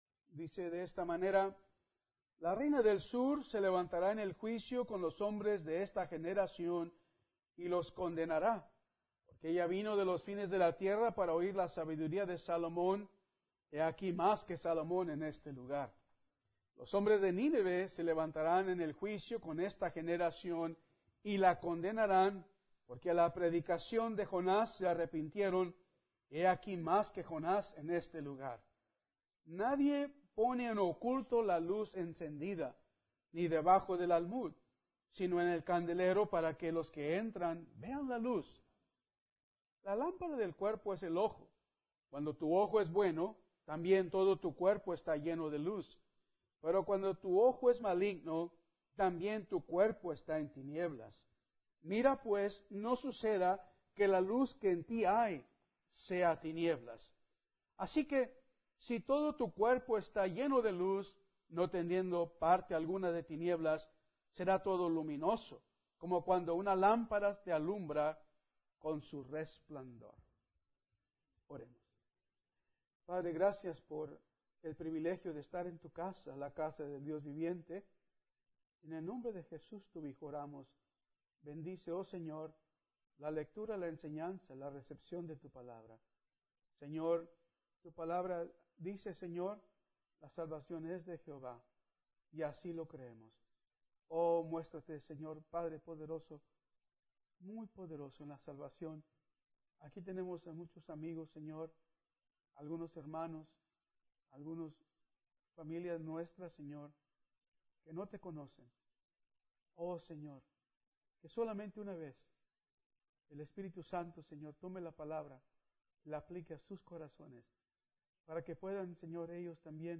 Domingo por la mañana – Lucas 11